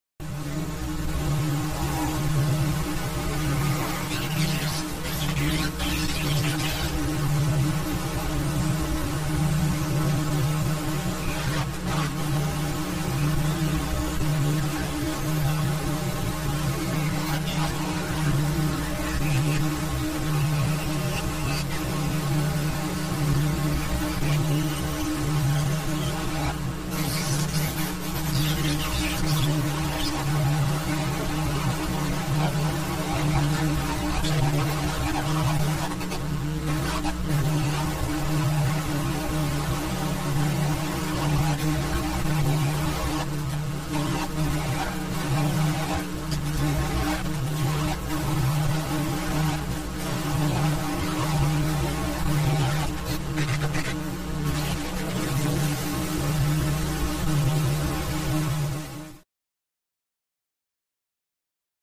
Alien Broadcast; Electronic Interference With Strange Vocalization.